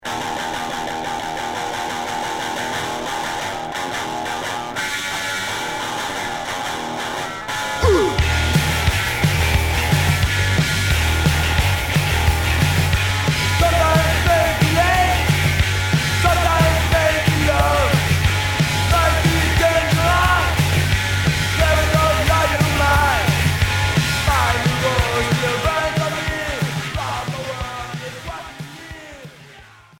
Garage punk